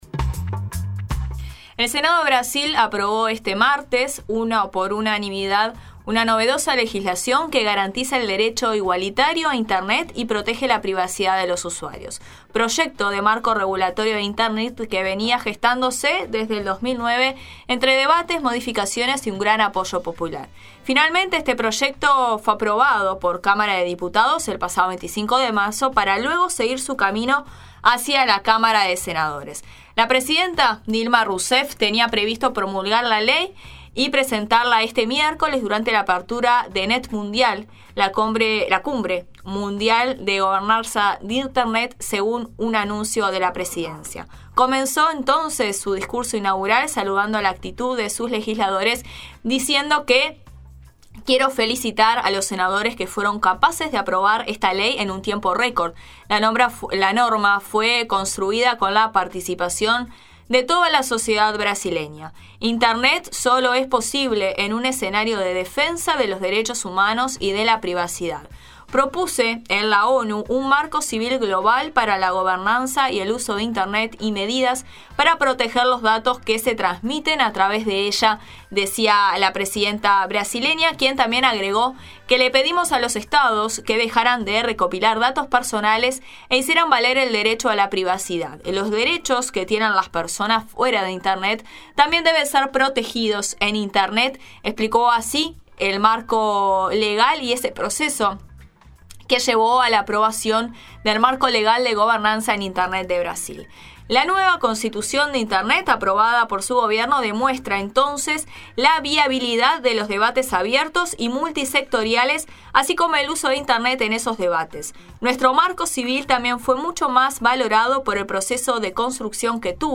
La Nueva Mañana realizó un informe acerca de los puntos más importantes tratados en esta ley, contando con la opinión de diversos actores que participaron en ella desde sus comienzos y analizando la importancia del logro de la misma teniendo en cuenta los sucesivos casos de filtración de información por parte de Estados Unidos.